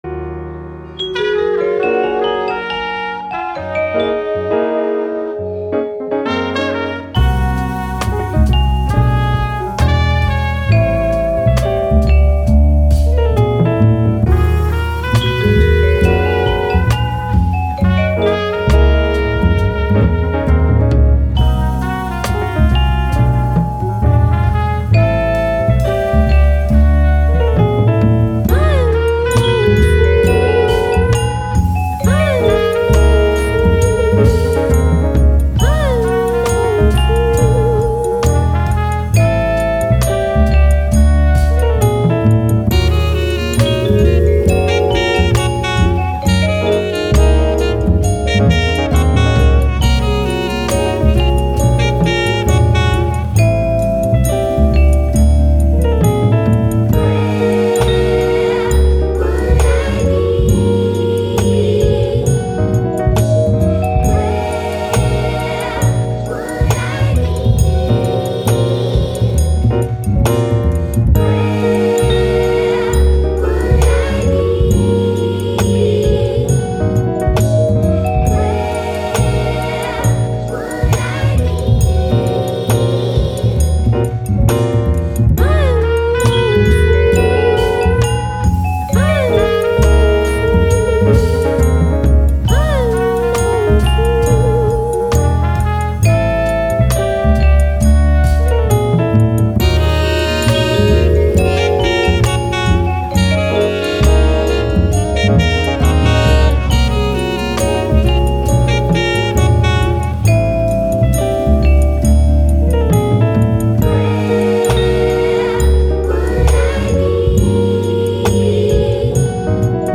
Jazz, Cool, Chilled, Night, Brass